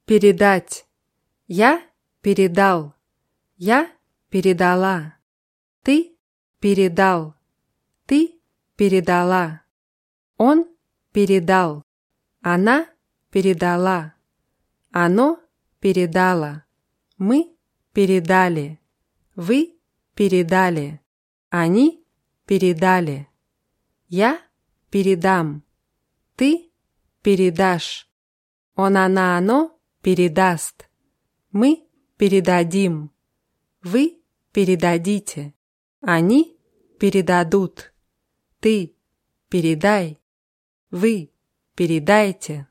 передать [pʲirʲidátʲ]